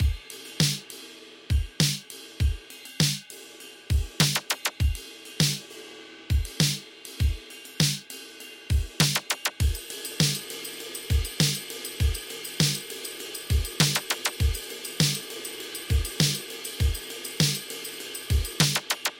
描述：一些老式的电子鼓在100bpm的速度下，有一些敲击的踢腿，有力的小军鼓，叮当的帽子，当然还有Zappys请欣赏